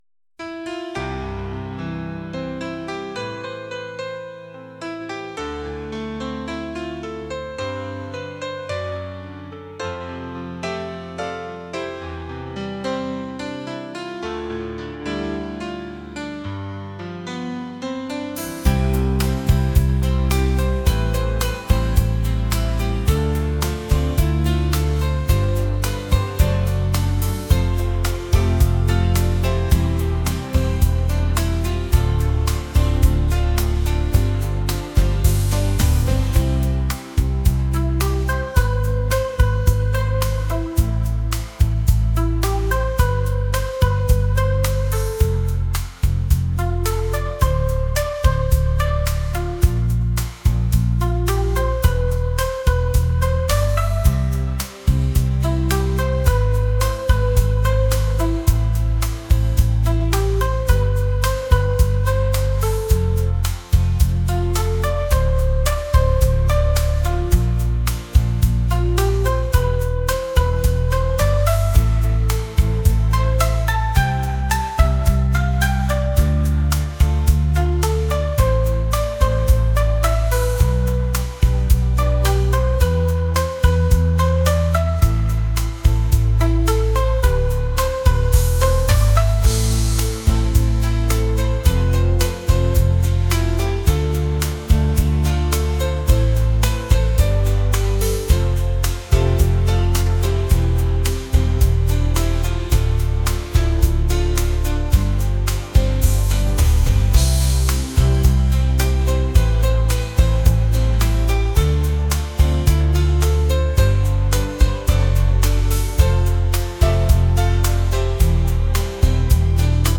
pop | soul & rnb | cinematic